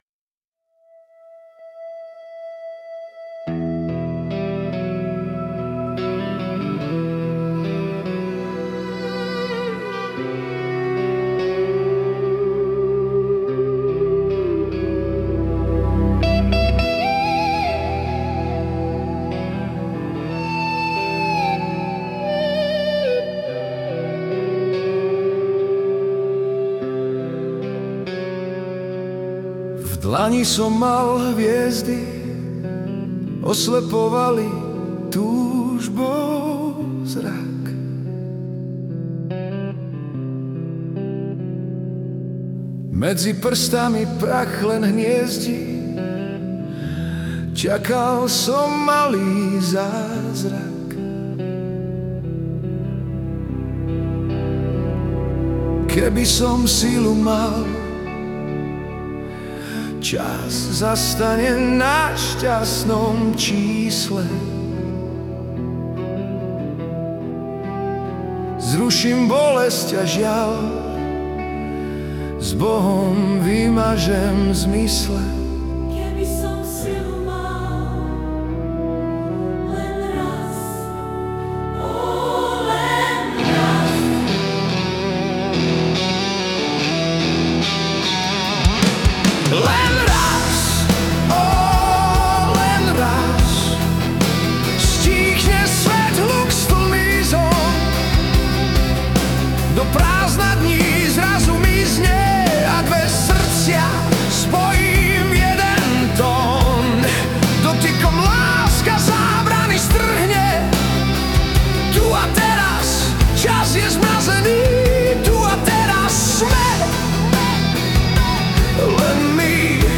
Hudba a spev AI
Balady, romance » Ostatní